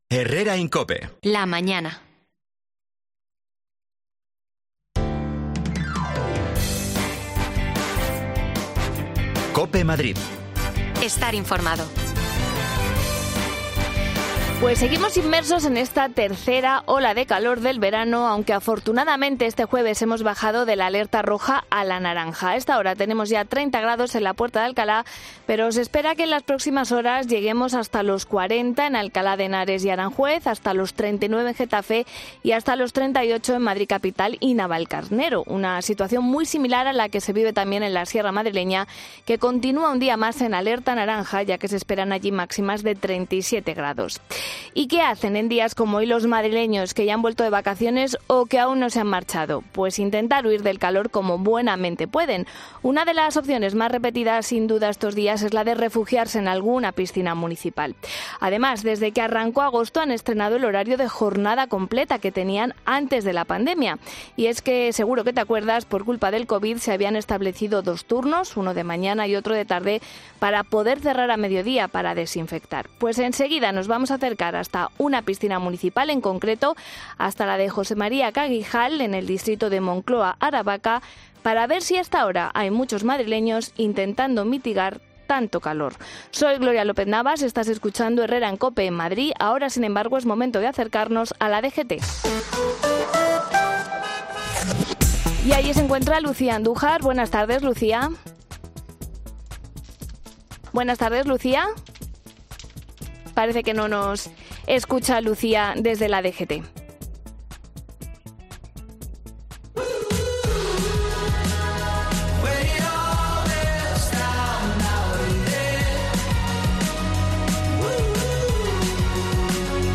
Nos vamos a una piscina municipal para comprobarlo.